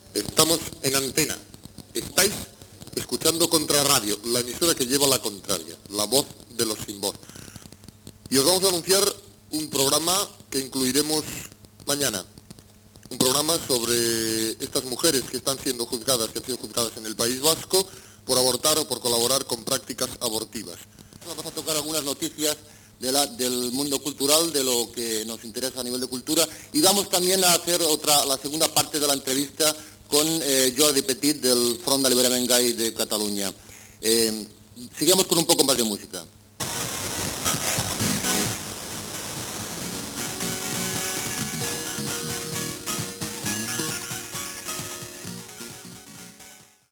Identificació "la voz de los sin voz". Anunci del programa sobre les dones jutjades a Euskadi per avortar i de l'entrevista a Jordi Petit del Front d'Alliberament Gai de Catalunya.